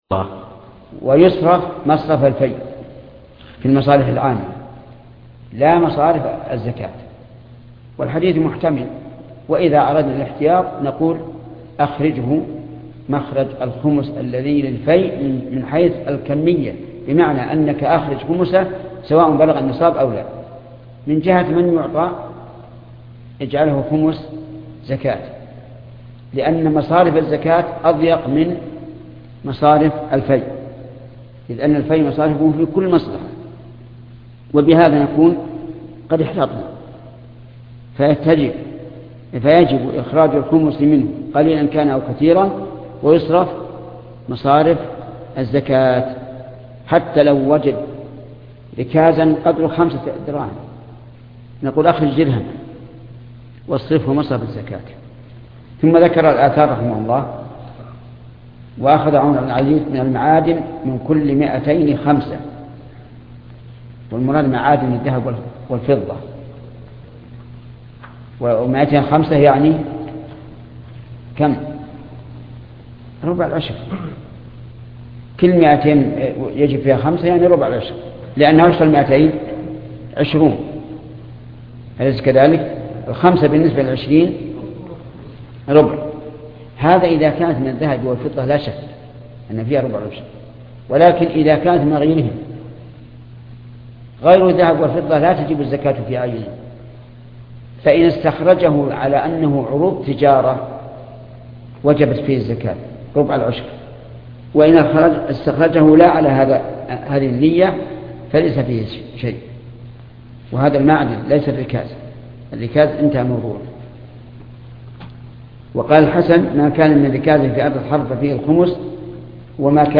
) قراءة من الشرح مع تعليق الشيخ من ساق الهدي ليس له أن يفسخ من العمرة من أخذ معه الدراهم ليشتري الهدي هناك هل يعتبر أنه ساق الهدي ؟